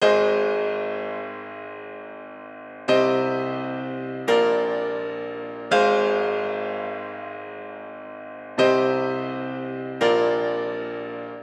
Тембр пиано
Я то слышу такие примитивные вещи, что в оригинале низы прибраны, ревер есть, ну это мне ничем не помогает, даже хоть более-менее приблизится.